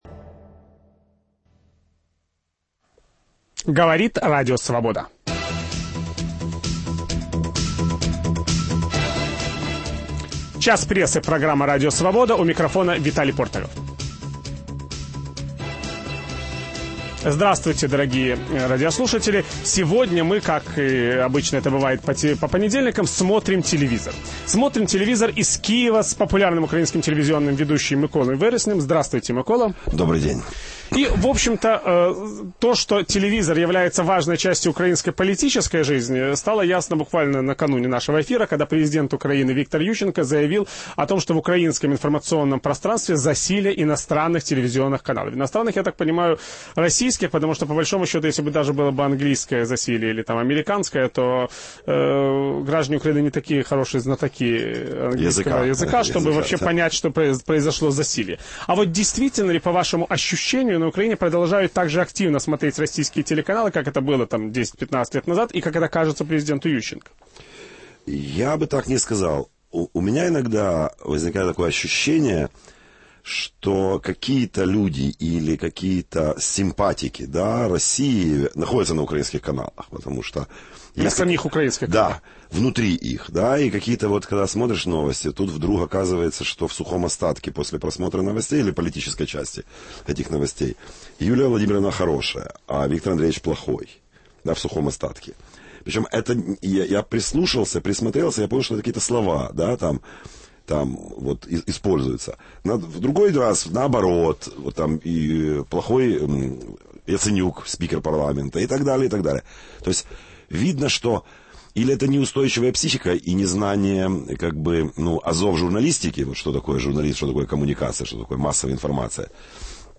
Как развивается сегодня украинское телевидение? В программе "Смотрим телевизор" из Киева Виталий Портников беседует с известным украинским телеведущим Миколой Вереснем.